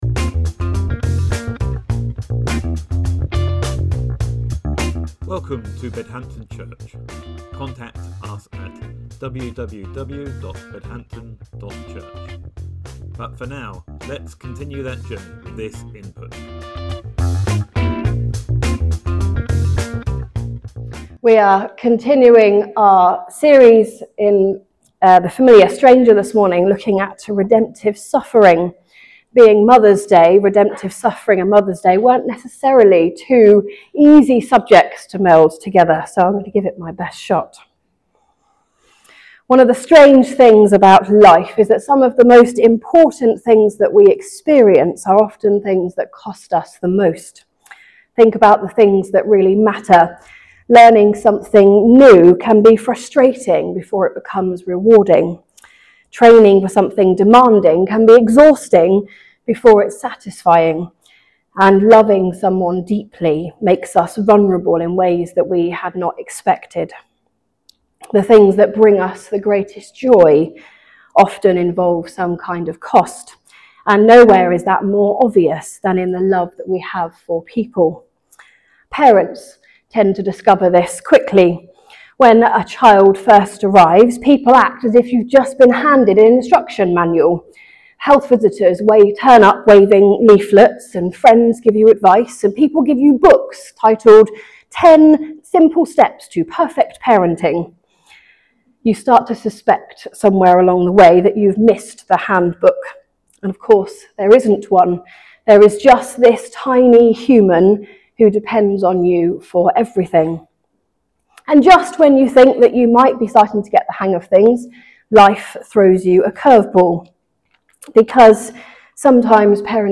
In The Familiar Stranger sermon series, we reflect upon his book as Tyler Staton reintroduces this oft-neglected Person of the Trinity, tracing the story of the Holy Spirit as it unfolds throughout the Bible, and inviting believers to close the gap between what Scripture reveals about the Holy Spirit and their lived experience.